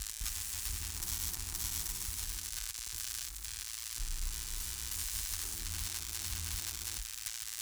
Weapon 16 Loop (Laser).wav